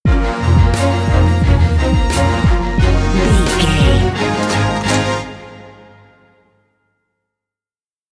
Epic / Action
Fast paced
In-crescendo
Aeolian/Minor
B♭
strings
drums
horns